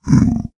Sound / Minecraft / mob / zombiepig / zpig3.ogg